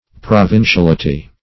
\Pro*vin`ci*al"i*ty\
provinciality.mp3